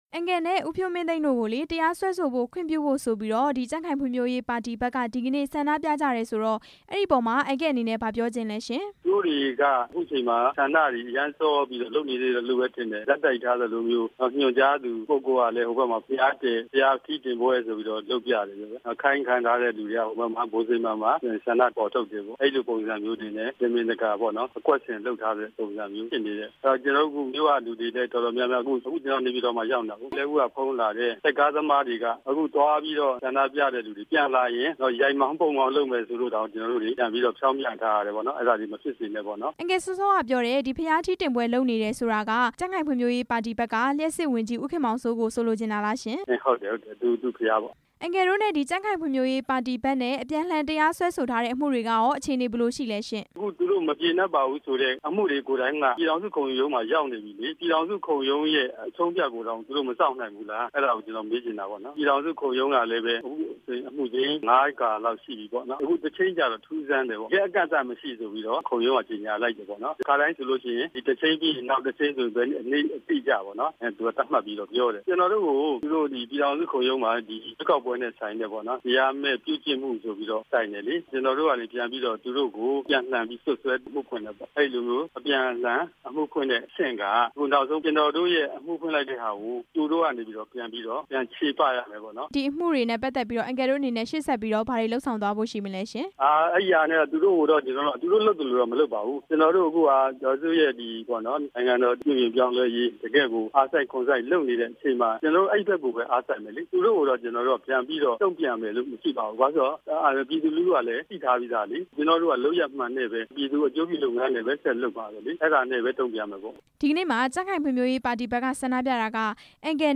ဦးသိန်းတန် ကို မေးမြန်းချက်